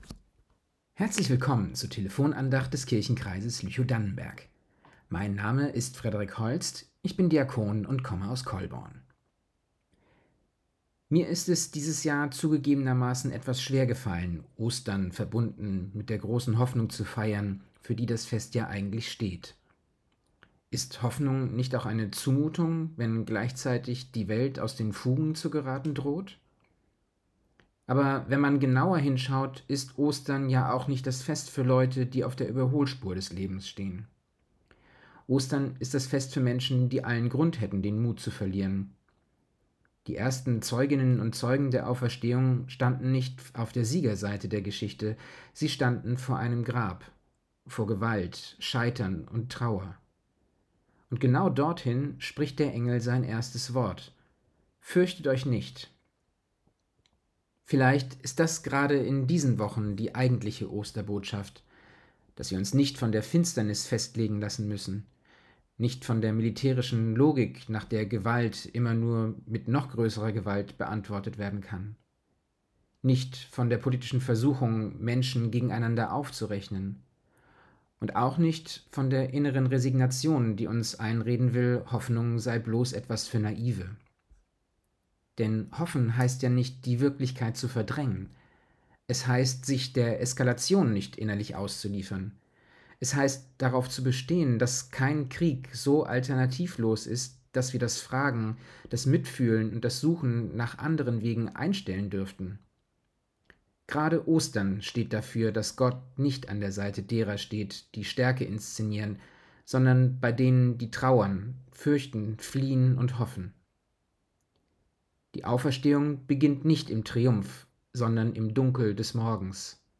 Telefon-Andacht